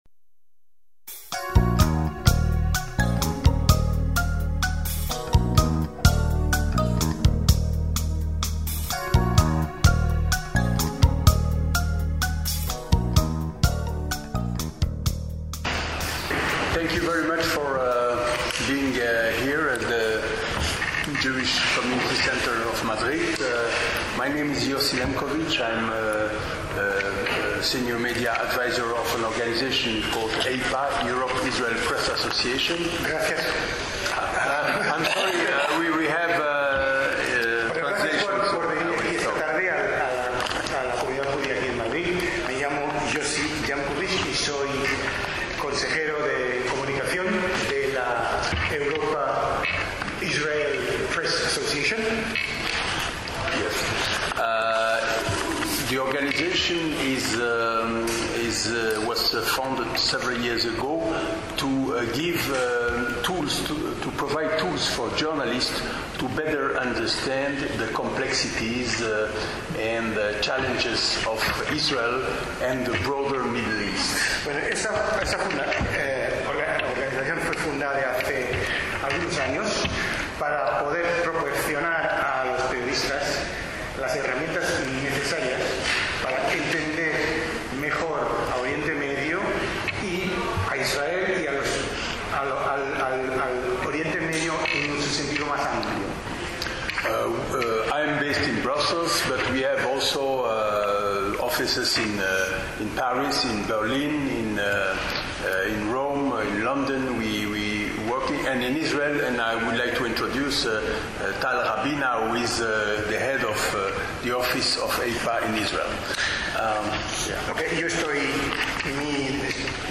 Taub también es una voz destacada internacionalmente en el discurso sobre el sionismo (en inglés con traducción).